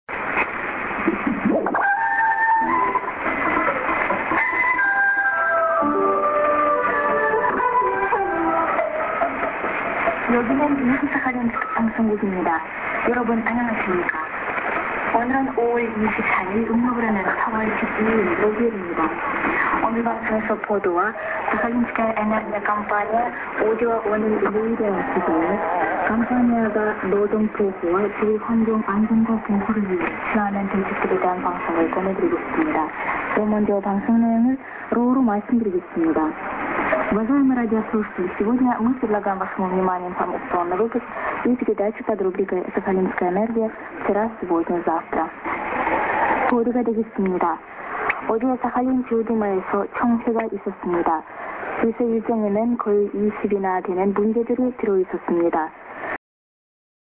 朝鮮語の開始 ->ID(women)->　USB R.Sakharinsk(Radio Rossii)